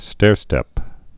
(stârstĕp)